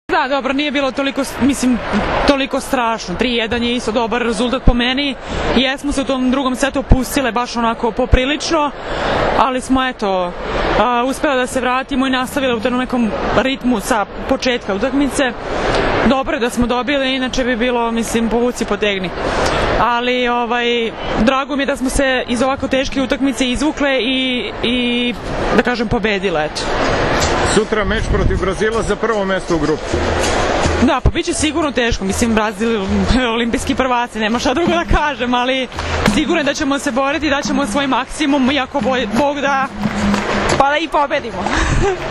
IZJAVA STEFANE VELJKOVIĆ